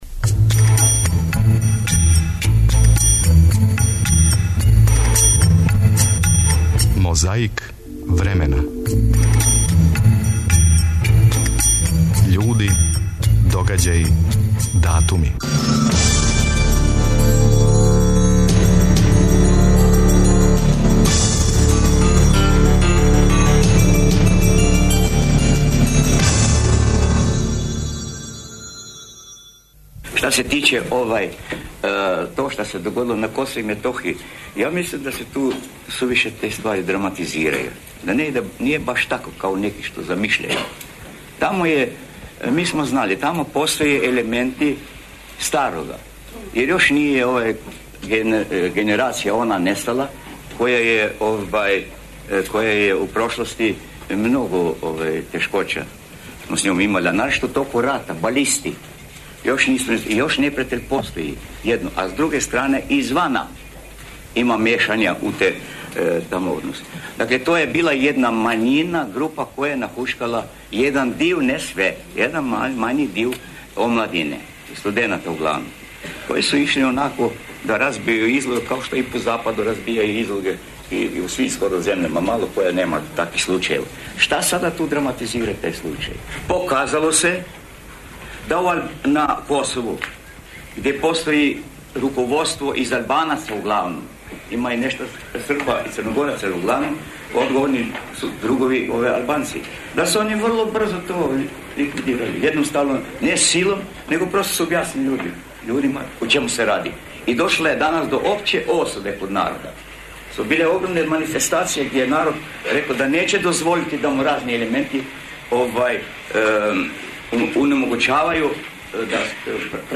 Борбу против пилећег памћења ове суботе отвара Јосип Броз Тито. Чућете како је зборио новембра 1968. године.
Захваљујући тонском архиву Радио Београда, сећамо се сећања на тај дан.
У емисији ћете чути шта је рекао капитен победничког тима, Јурица Јерковић.